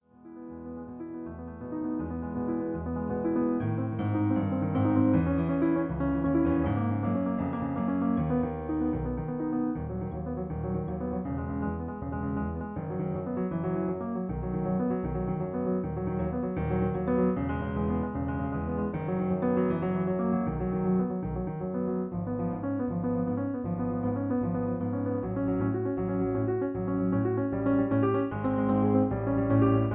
In E. Piano Accompaniment